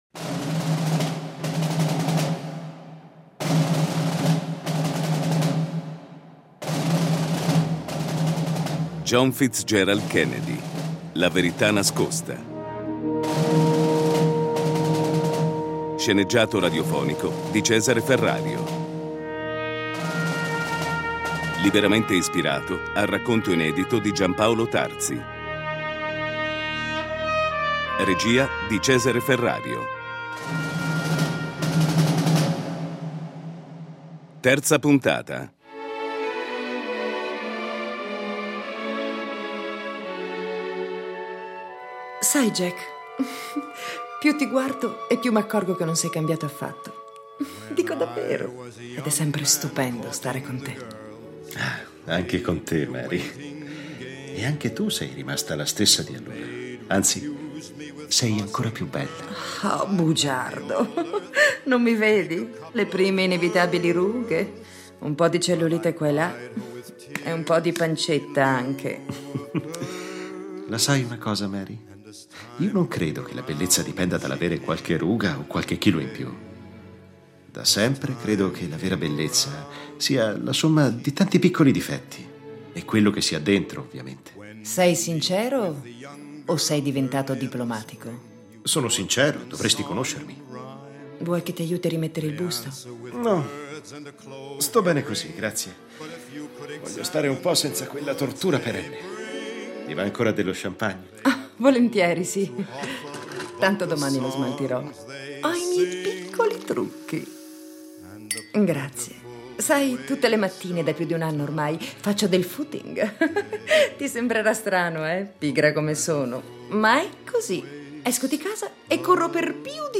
Domande che, sorrette da una forte impalcatura drammaturgica e attraverso il potere fascinatorio ed evocativo di un racconto variegato, vergono rimbalzate all’attenzione dell’ascoltatore a cui spetterà il compito di trovare nuove risposte ai numerosi interrogativi.